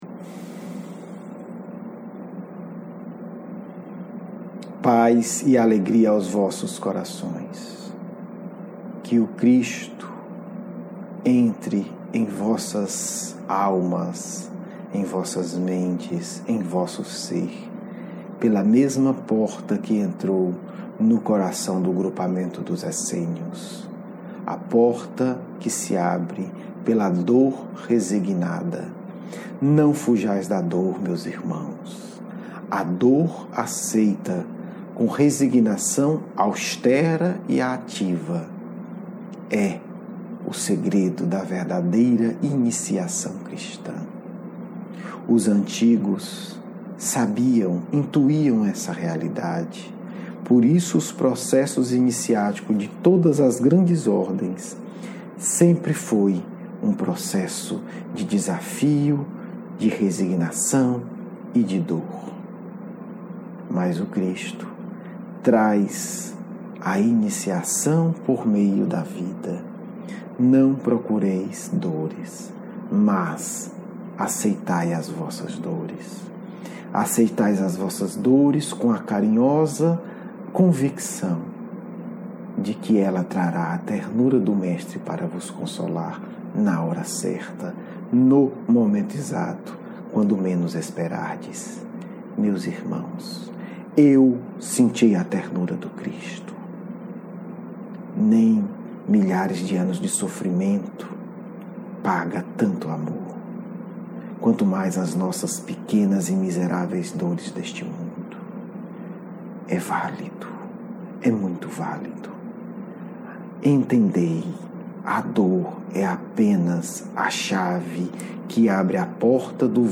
Diálogo Mediúnico (2019)